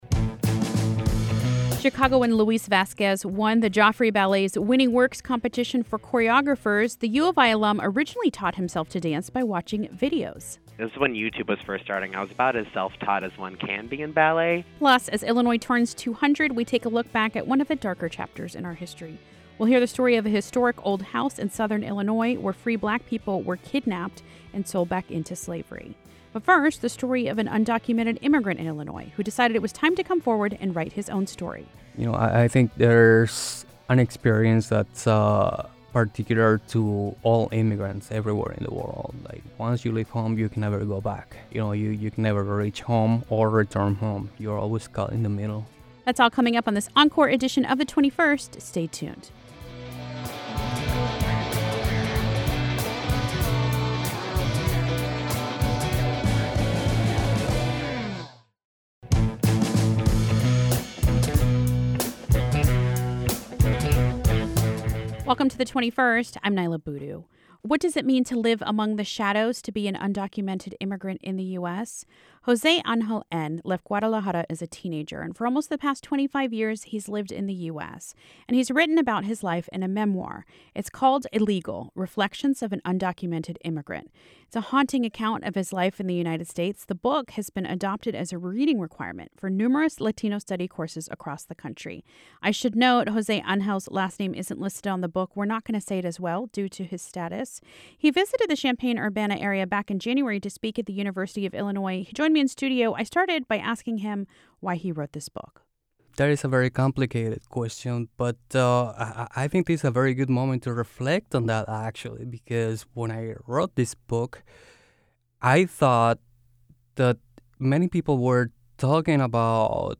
Plus, the story of a house in southern Illinois whose owner kidnapped free black men and women -- and sold them back into slavery in Kentucky. And, we revisit our interview about the Joffrey Ballet's 8th Annual 'Winning Works' competition.